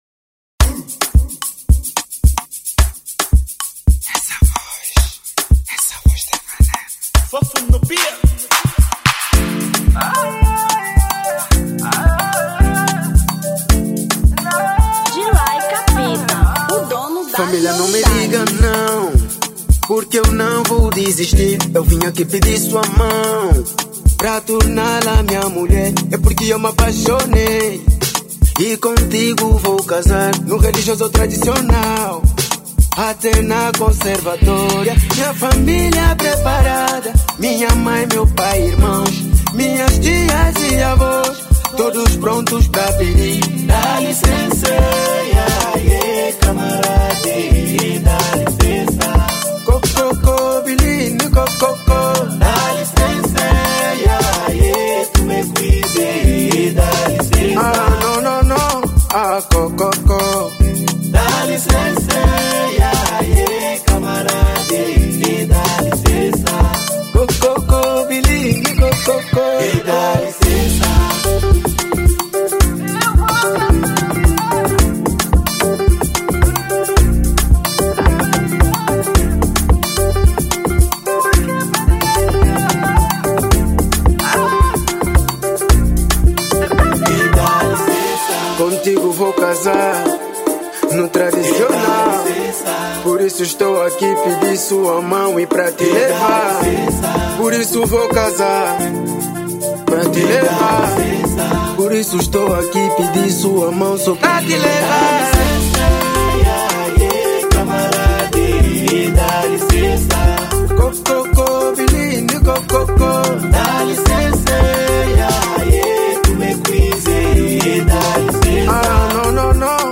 Amapiano 2025